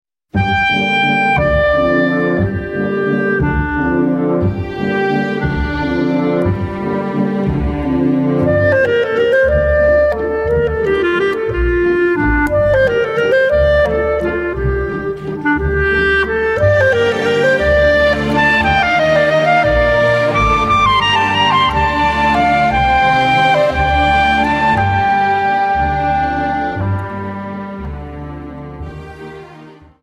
Viennese Waltz 58 Song